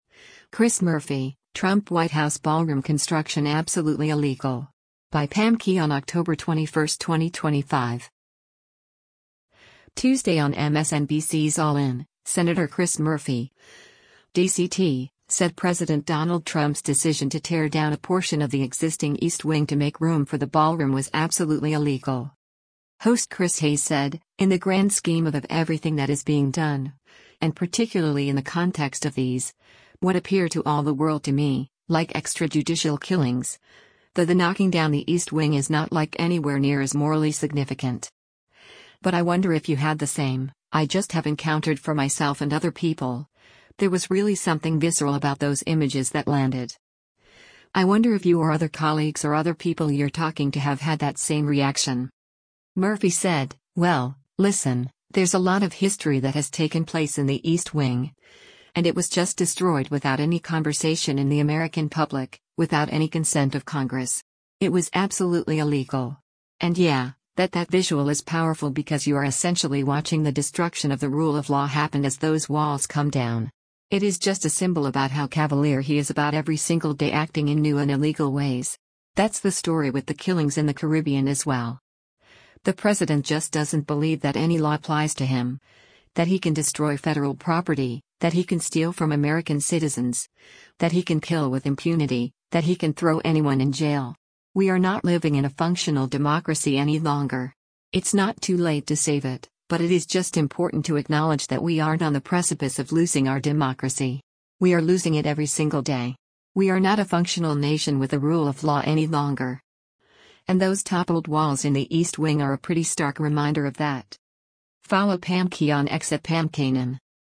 Tuesday on MSNBC’s “All In,” Sen. Chris Murphy (D-CT) said President Donald Trump’s decision to tear down a portion of the existing East Wing to make room for the ballroom was “absolutely illegal.”